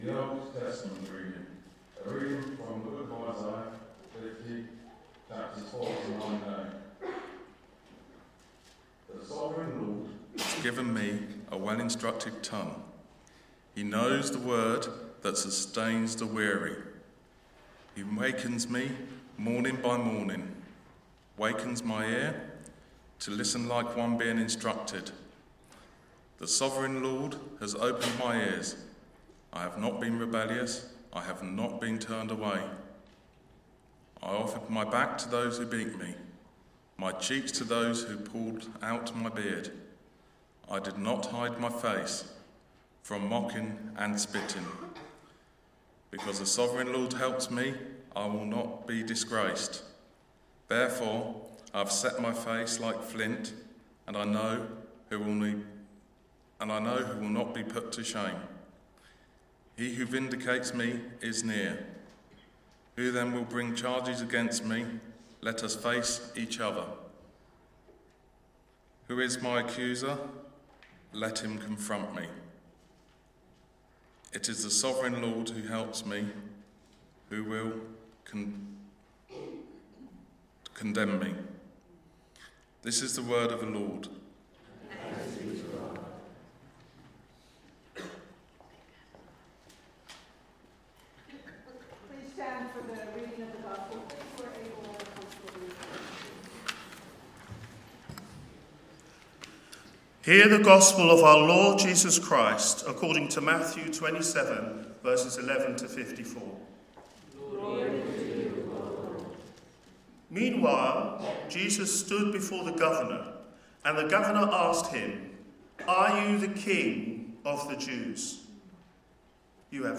“Who Is This Who Comes?” – Palm Sunday Sermon: On Palm Sunday, the crowds welcomed Jesus into Jerusalem with joy, shouting, “Hosanna!